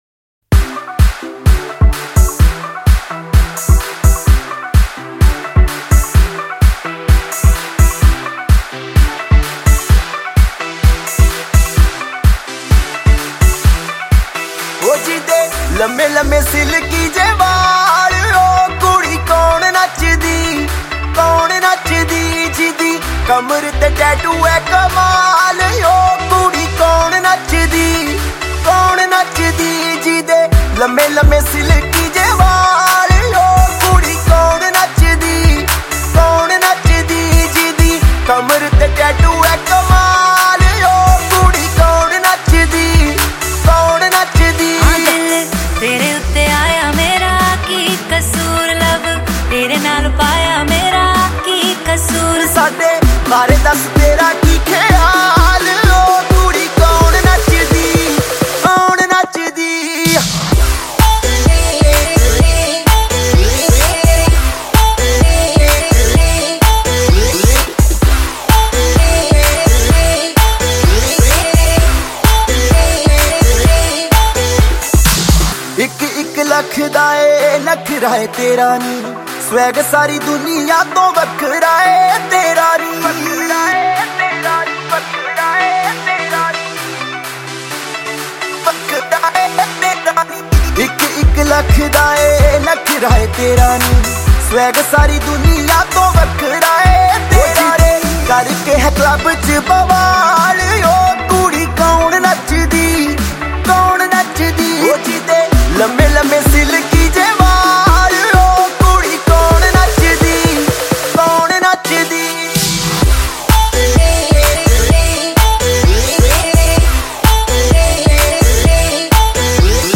Happy Local Song of Indian
دانلود آهنگ محلی شاد هندی